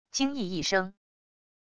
惊异一声wav音频